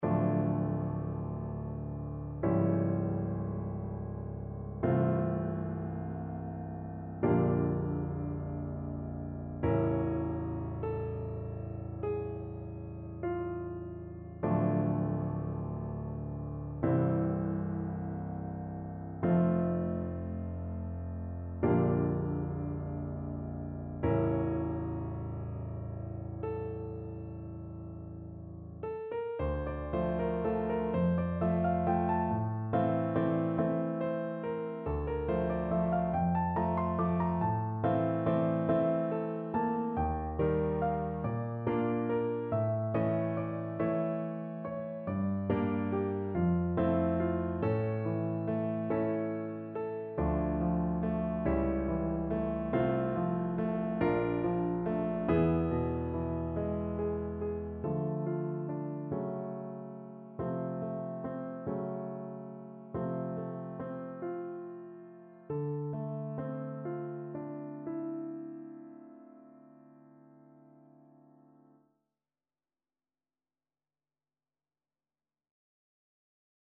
Theme and variations